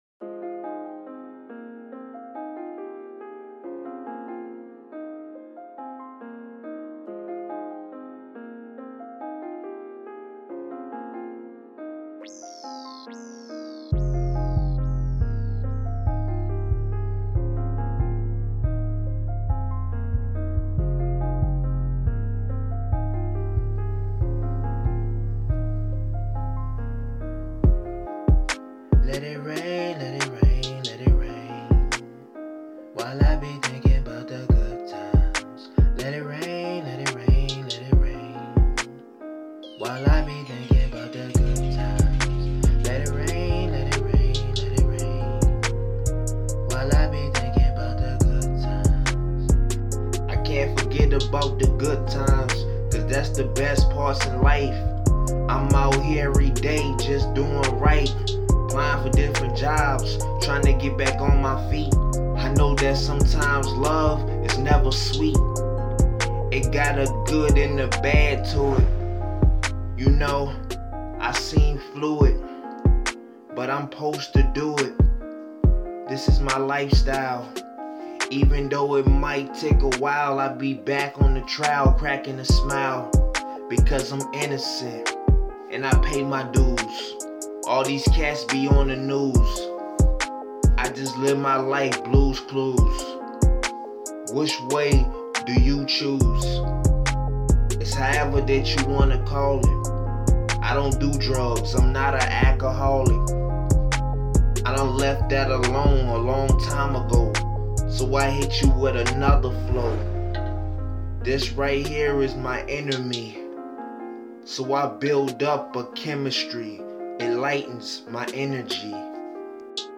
Hiphop
passionate song